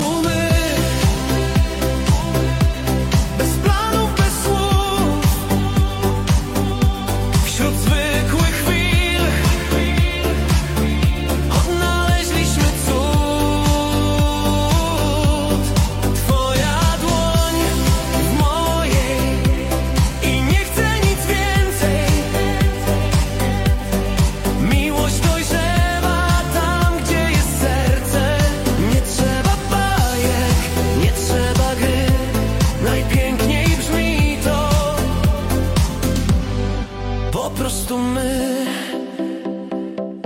Disco Polo - wykonawca - Disco polo